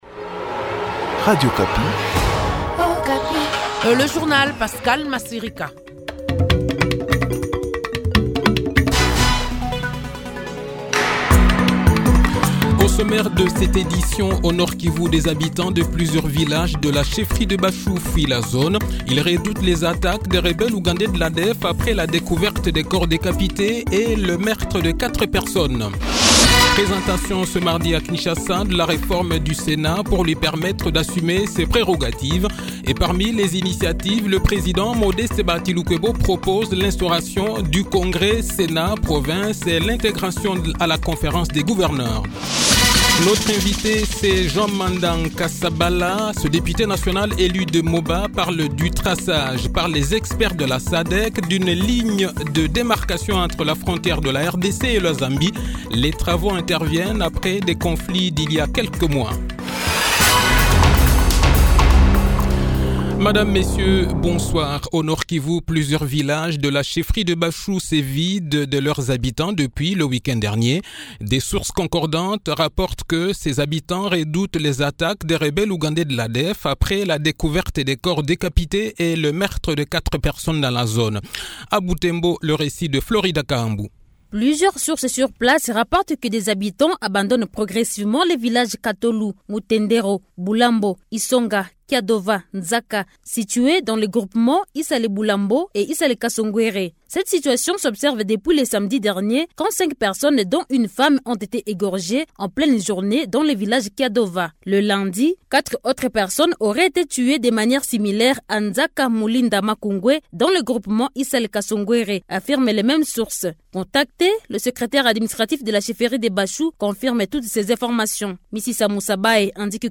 Le journal de 18 h, 14 septembre 2021